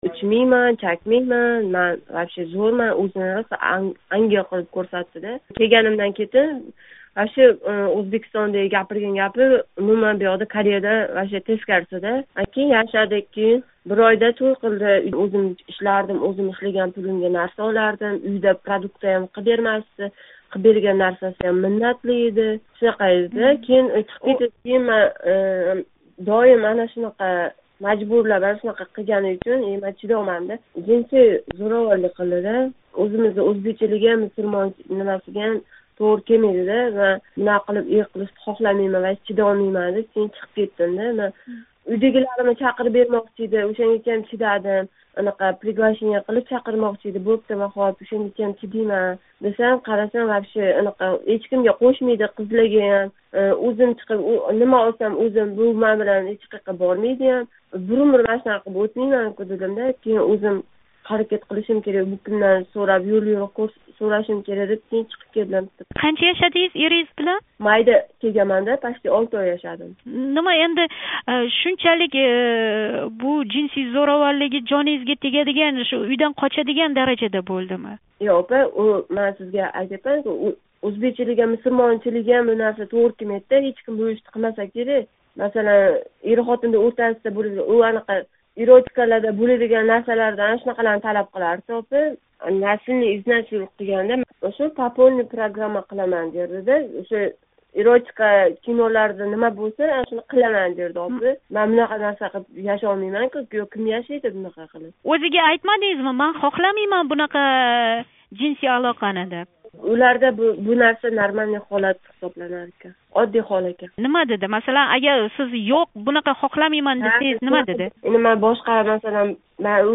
Кореяга келин бўлиб тушган ўзбекистонлик қиз билан суҳбат.